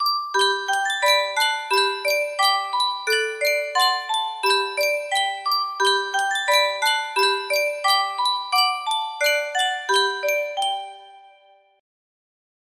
Sankyo Music Box - The Wheels on the Bus BRB music box melody
Full range 60